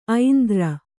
♪ aindra